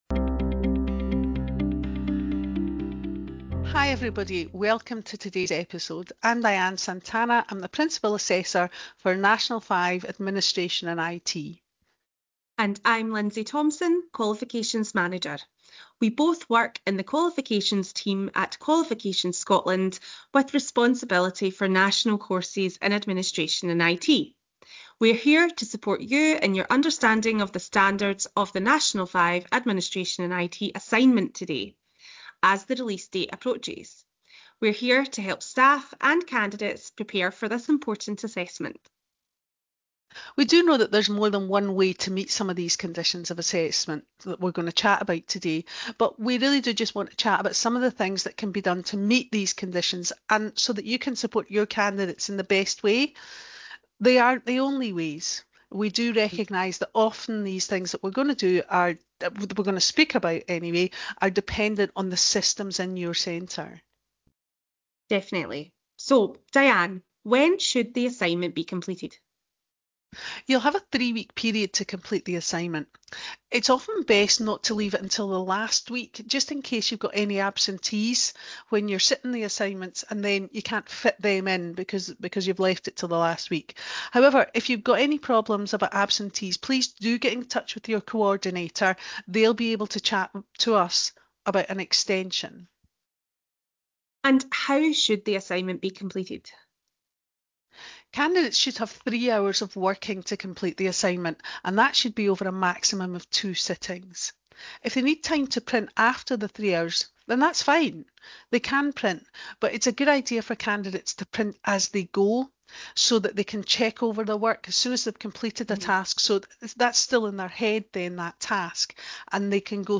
Administration and IT - Audio Discussions